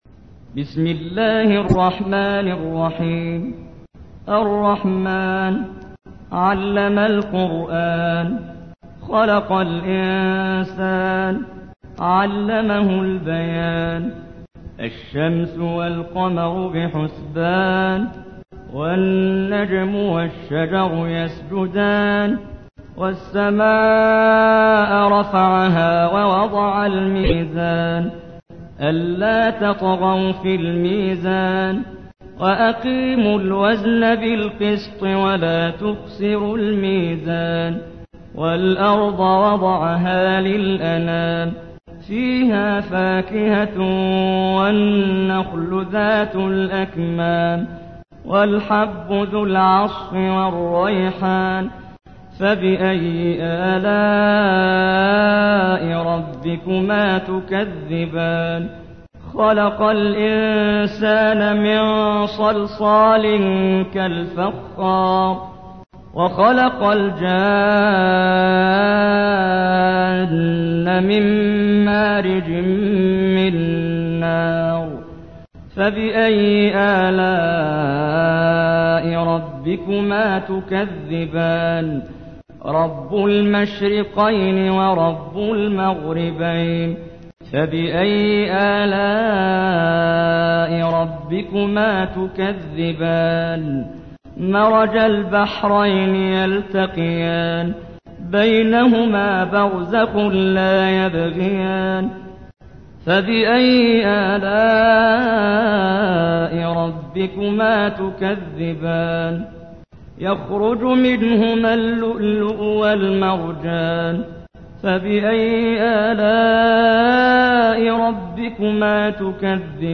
تحميل : 55. سورة الرحمن / القارئ محمد جبريل / القرآن الكريم / موقع يا حسين